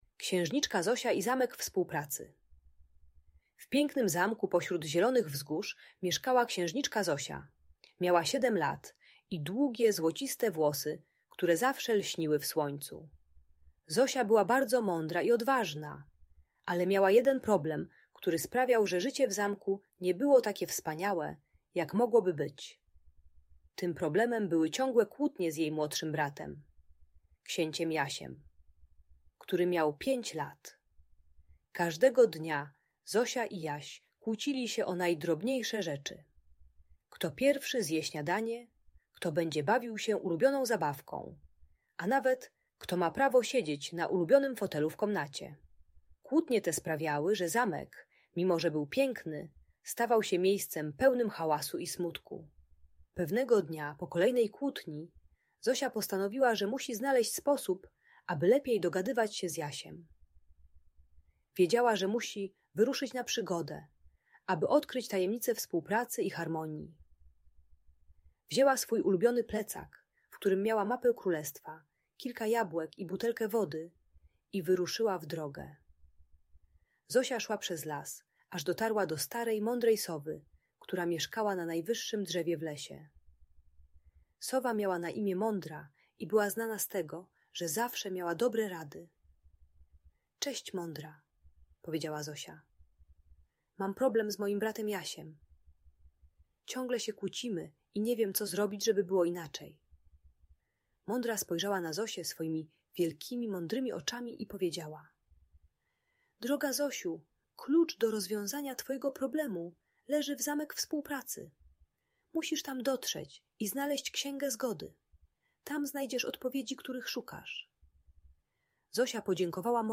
Księżniczka Zosia i Zamek Współpracy - Audiobajka